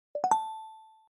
soundnotify.mp3